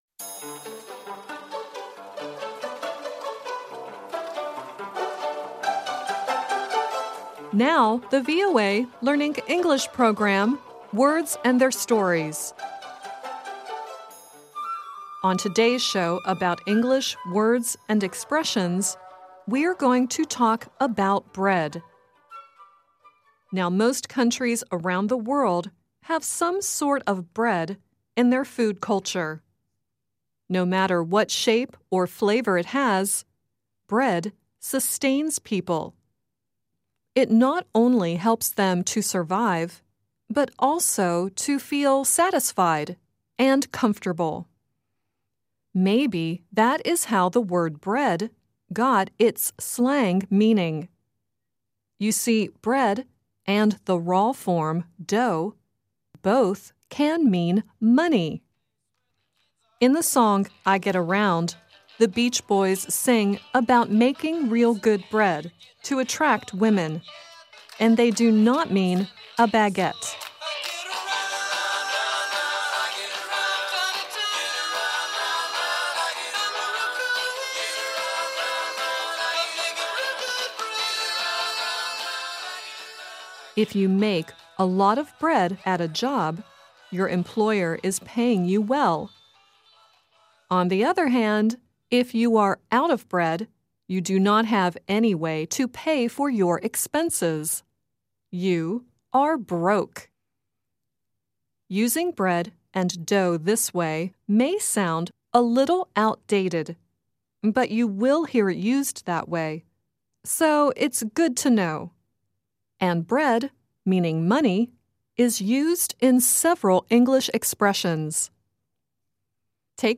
At the end of the show, David Bowie sings "Young Americans."